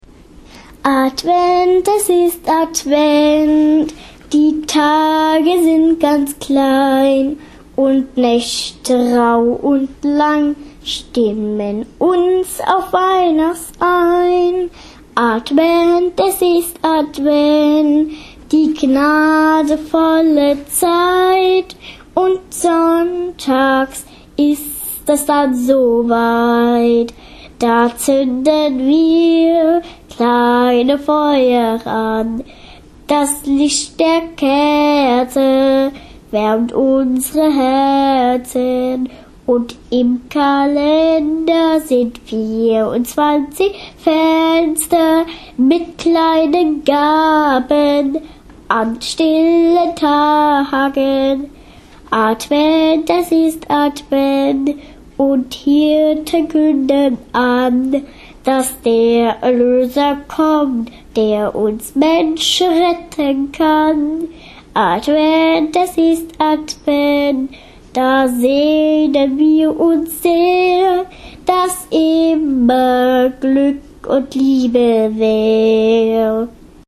Tonaufnahme der gro�en Glocke von Kleinlasseln